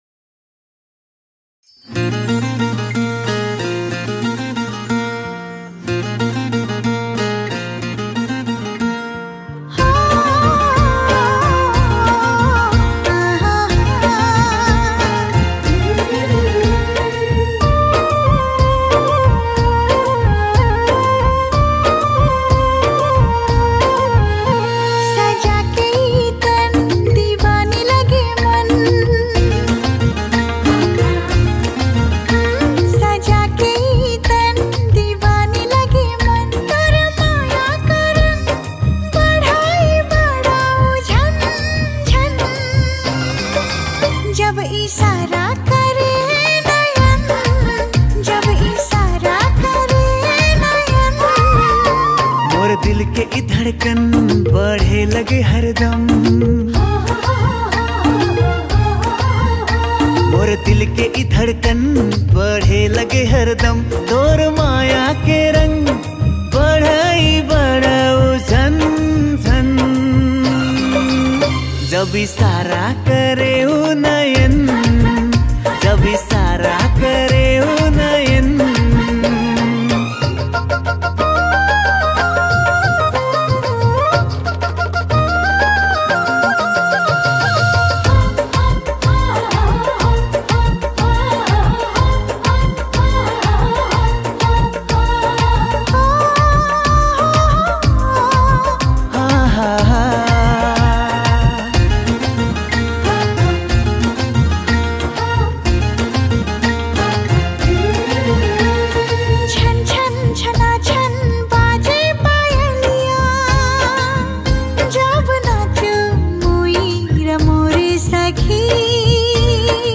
Tharu Romantic Song